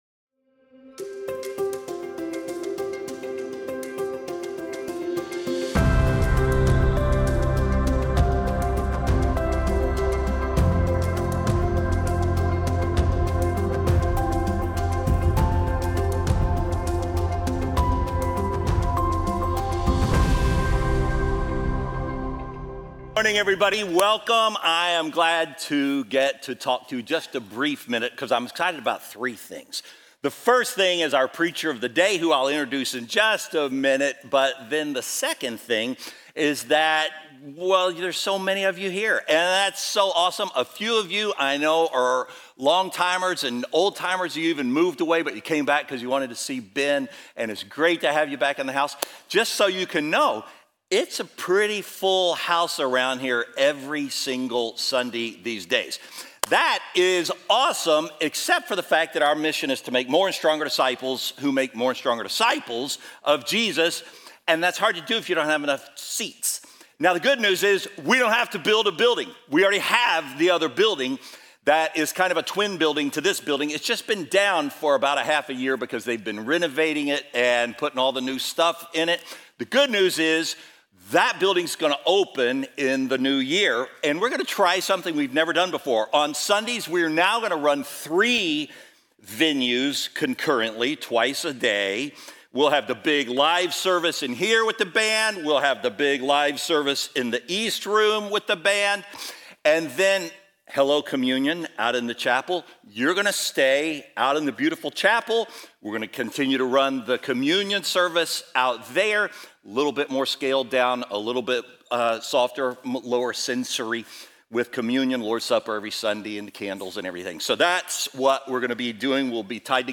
Weekly biblically-based sermons from Faithbridge church in Spring, Texas.